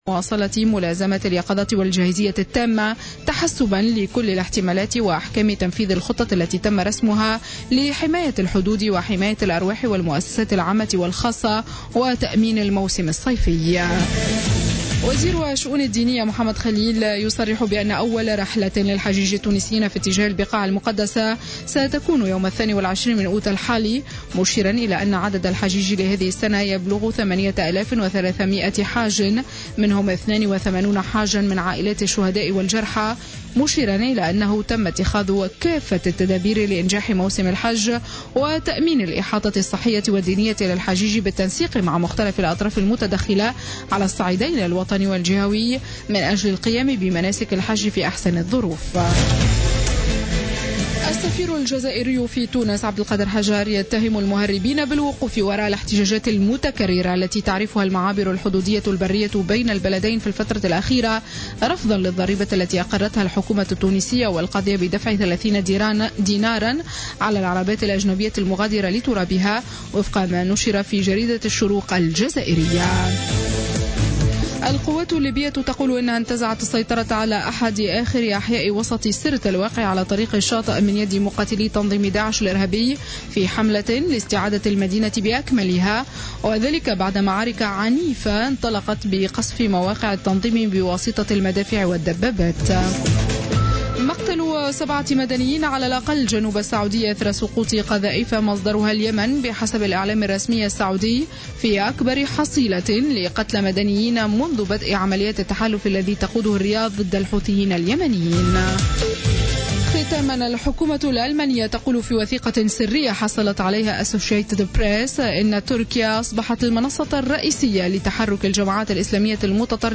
نشرة أخبار منتصف الليل ليوم الاربعاء 17 أوت 2016